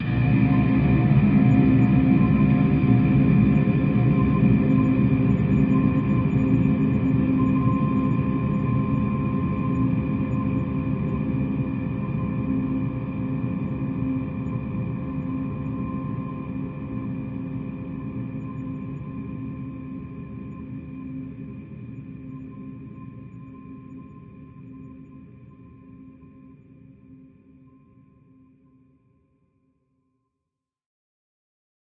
工业之声 " 巨大的旋转体
描述：由一台旧车床发出的声音；经过拉伸和处理，听起来像一台巨大的机器。用MAudio Microtrack II录制。用Audacity 2.0.3进行处理
标签： 环境 马达 电气 工业 电影 机械 噪声
声道立体声